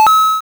pickup.wav